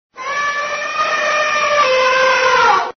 Elephant Ringtone
• Animal Ringtones